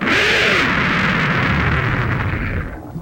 WanigonKingRampus_roar.ogg